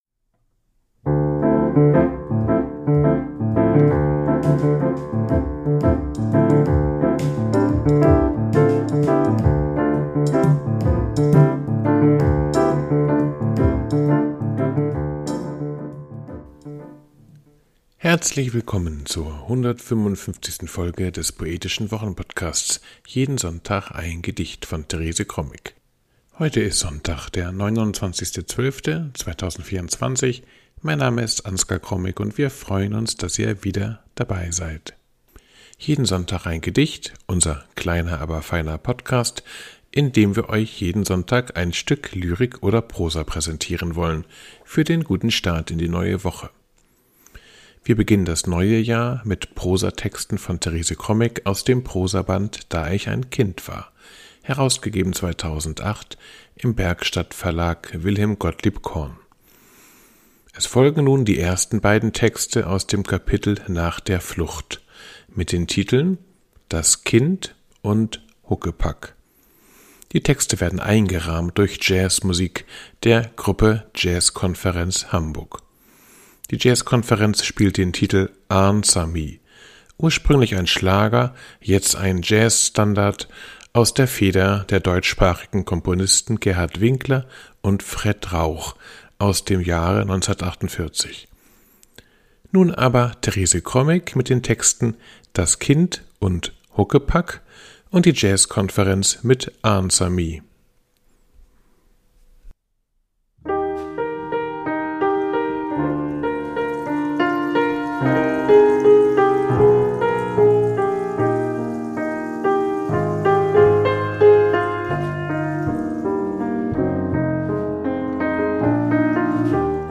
Die Jazzkonferenz spielt "Answer Me" Ursprünglich ein Schlager,